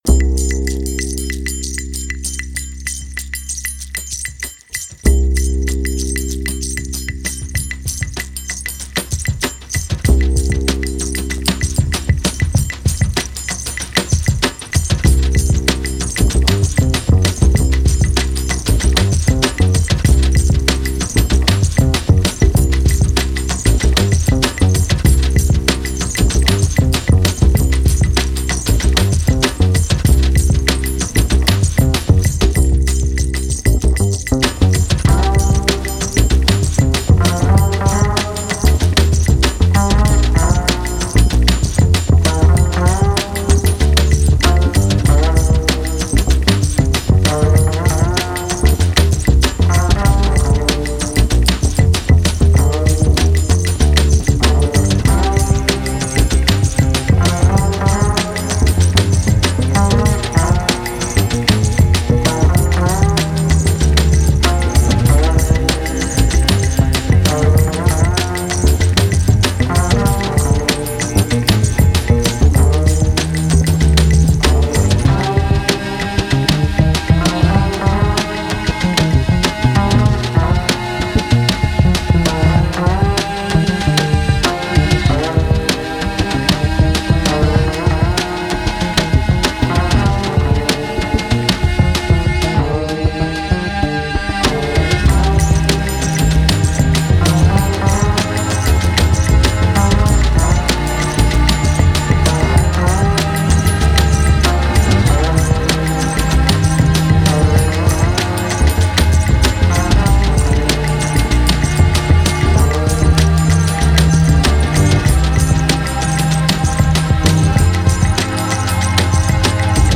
专辑类型：Lounge